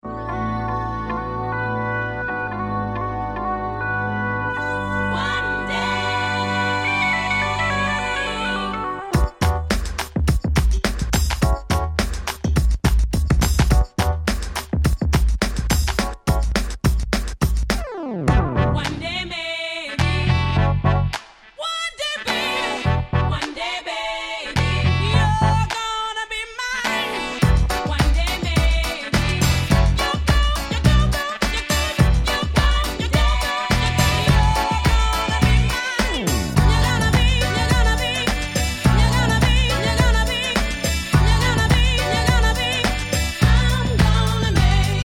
超絶キャッチー！！